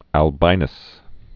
(ăl-bīnəs)